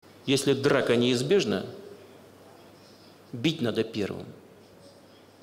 • Качество: высокое
Фраза Путина бить надо первым